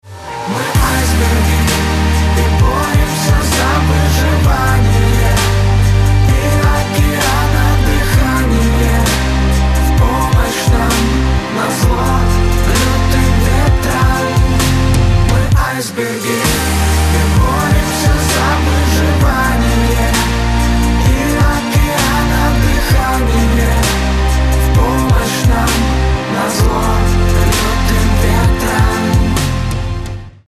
• Качество: 320, Stereo
поп
мужской вокал
dance
романтичные